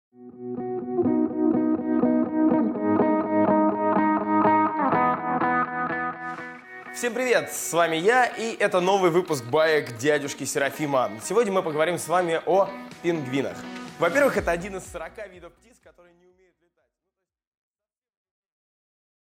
Аудиокнига Где колени у пингвинов?